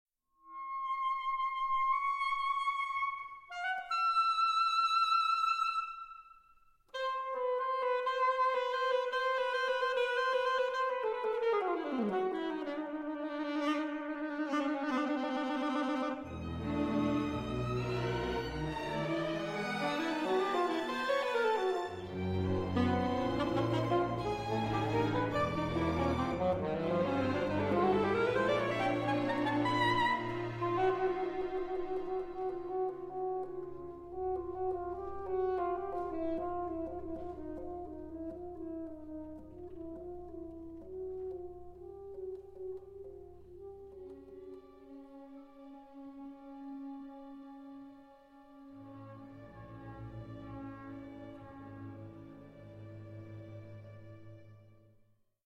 Instrumentation: alto saxophone, string orchestra